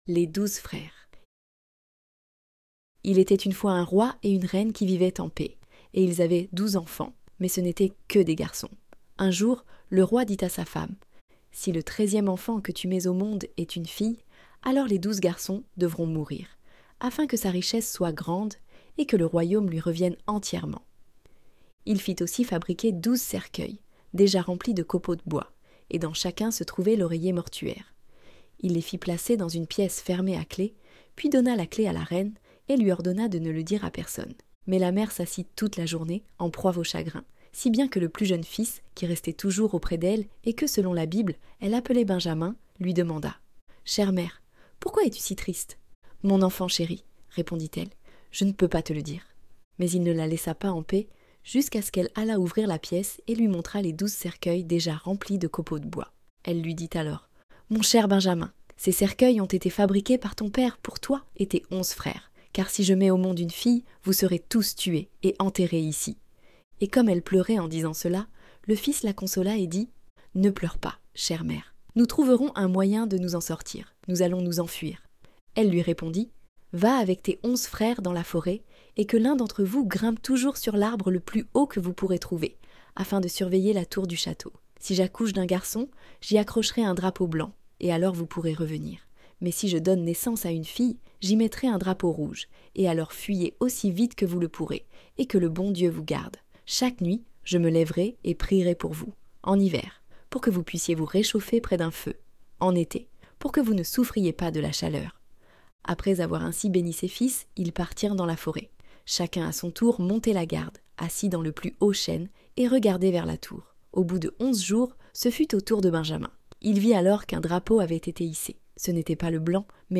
Les-douzes-freres-Conte-de-Grimm-9-Audio-livre-Contesdefees.com_.mp3